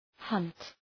Προφορά
{hʌnt}